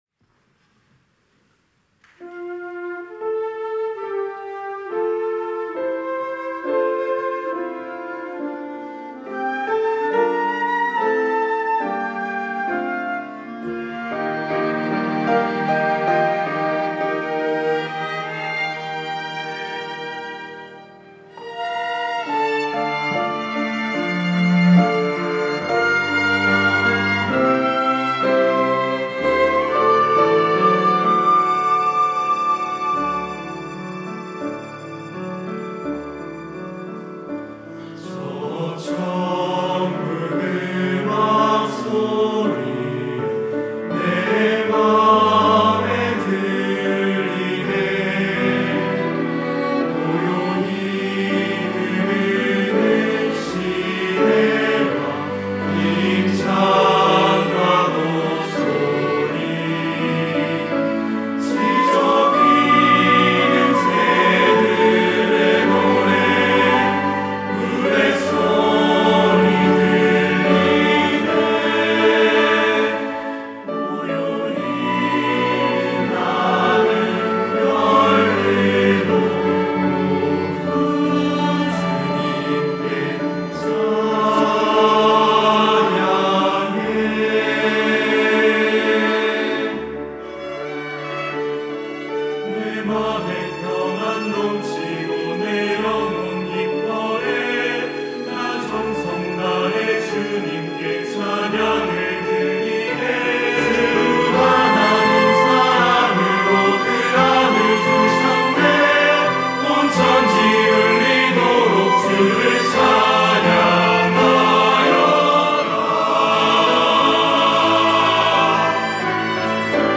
특송과 특주 - 저 천국 음악소리
청년부 로페카